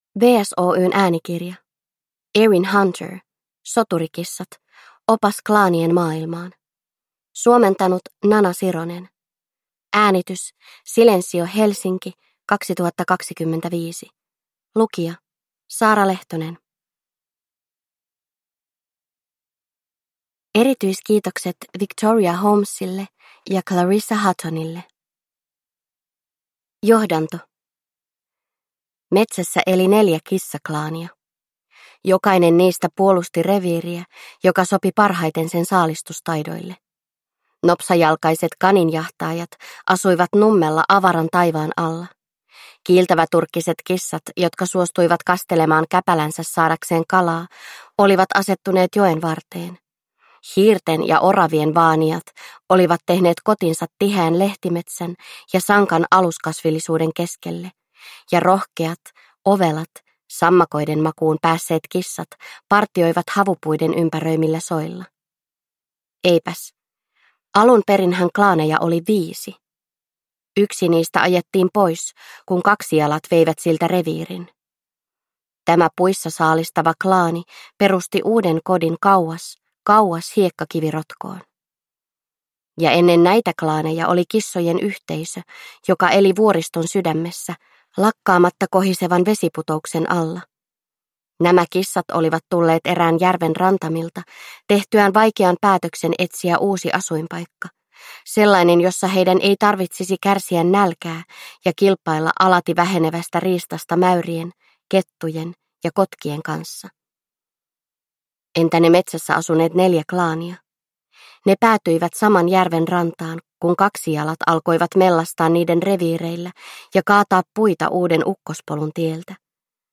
Soturikissat: Opas klaanien maailmaan – Ljudbok